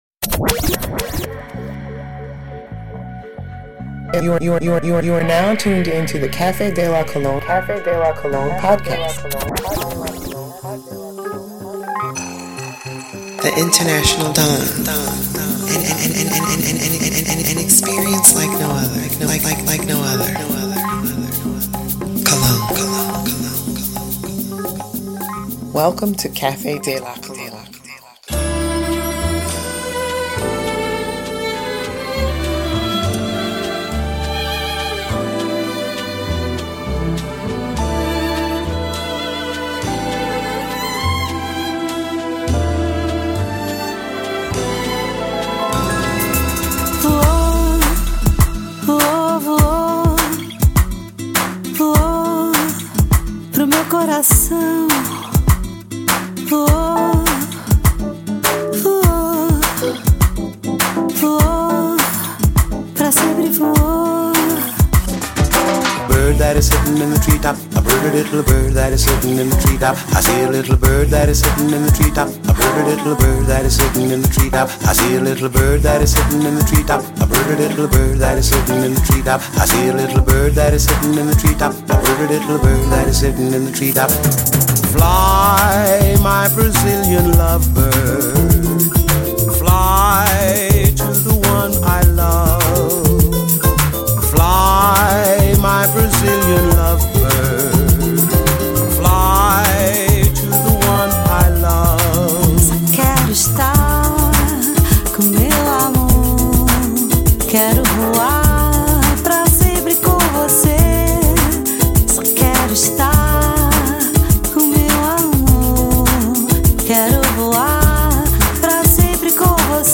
Exclusive designer music mixed